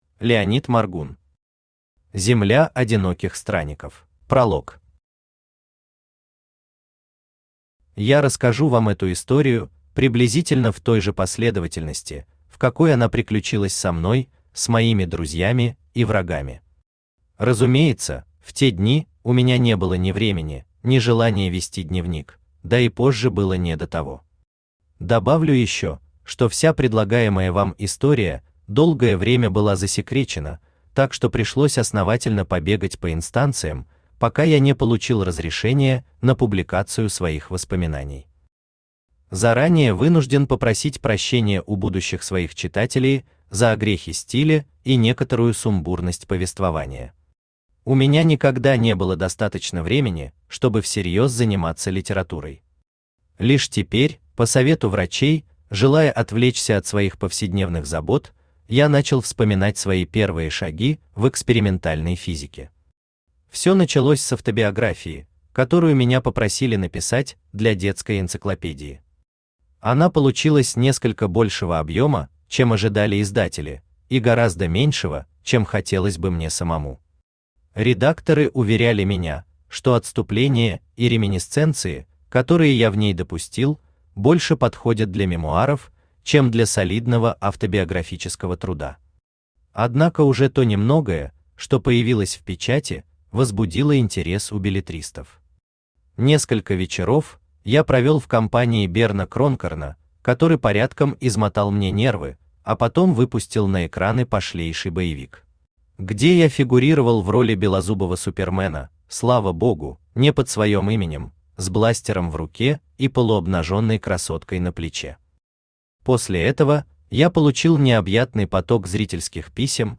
Aудиокнига Земля Одиноких Странников Автор Леонид Моргун Читает аудиокнигу Авточтец ЛитРес.